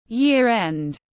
Cuối năm tiếng anh gọi là year end, phiên âm tiếng anh đọc là /ˌjɪər ˈend/
Year end /ˌjɪər ˈend/